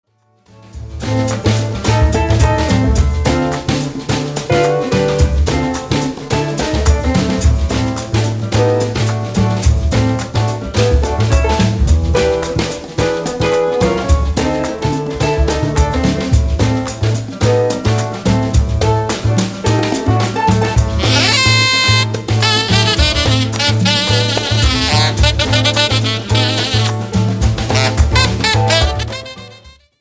FUNKY SOUL  (03.14)